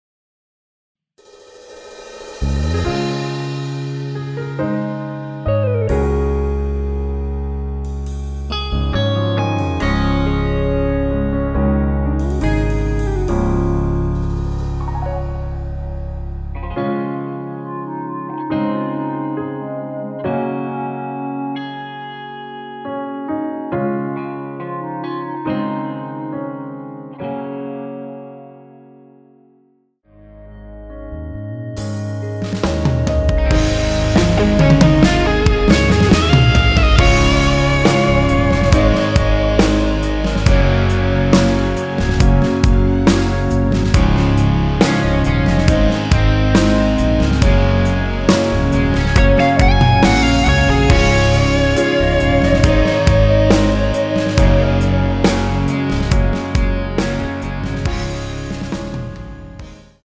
원키에서(-3)내린 멜로디 포함된 MR입니다.(미리듣기 확인)
Db
앞부분30초, 뒷부분30초씩 편집해서 올려 드리고 있습니다.
중간에 음이 끈어지고 다시 나오는 이유는